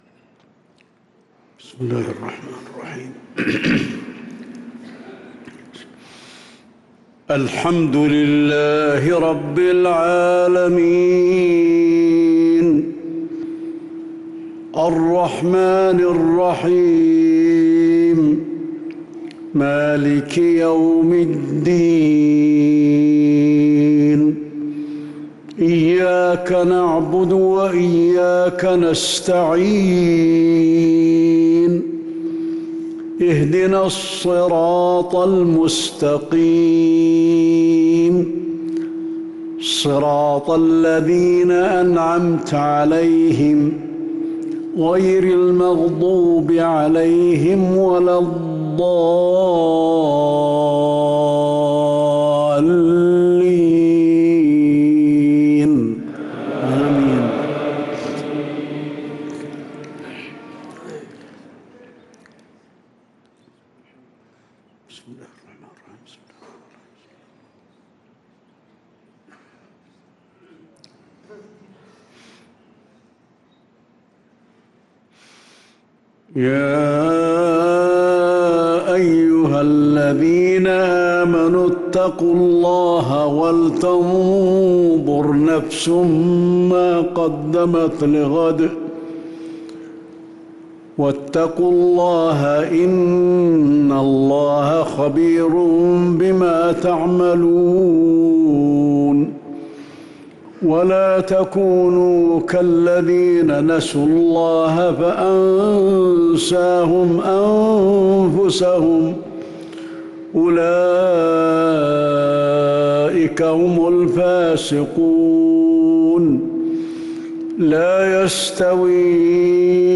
مغرب الاثنين 10 محرم 1444هـ | آخر سورتي الحشر و المنافقون | Maghrib prayer from Surah Al-Hashr & Al-Monafeqon 8-8-2022 > 1444 🕌 > الفروض - تلاوات الحرمين